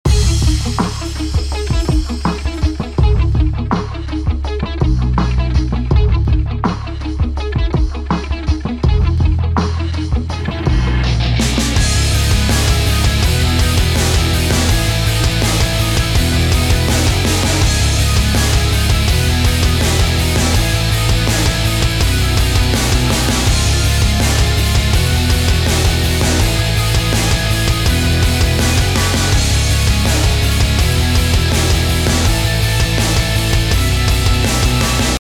Электрогитара "Ibanez RG 350 EX Black" .
Несколько фрагментов с этой гитарой: